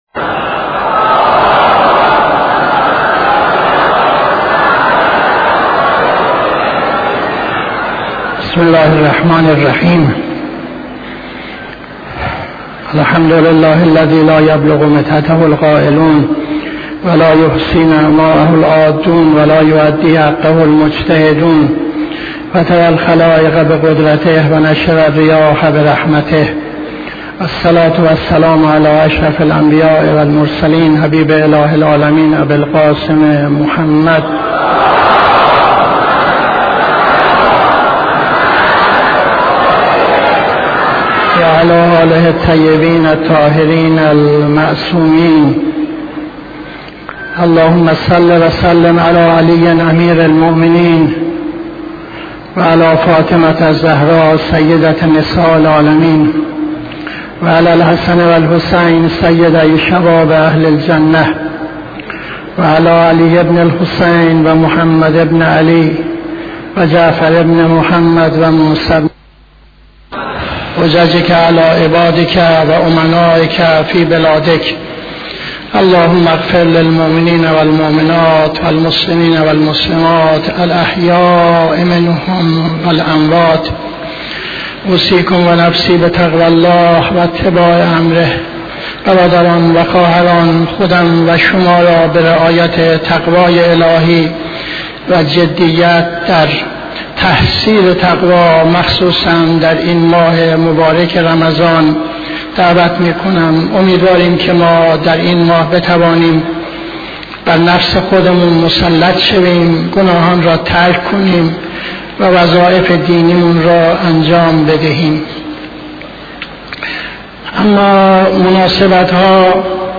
خطبه دوم نماز جمعه 19-09-78